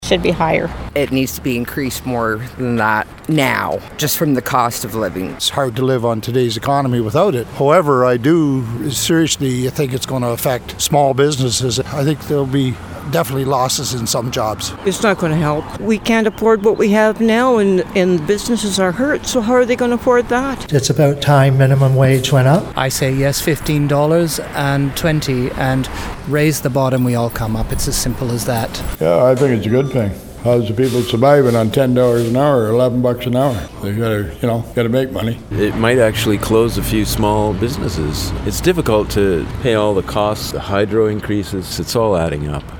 Residents tell Moose FM how they feel about it.